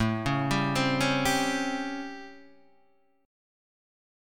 A7#9 chord {5 4 5 5 2 5} chord